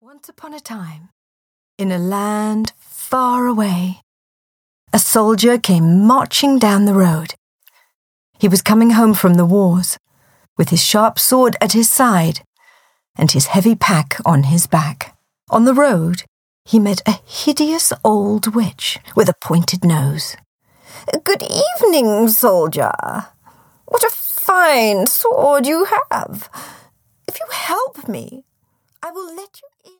The Tinderbox (EN) audiokniha
Ukázka z knihy
• InterpretEmma Samms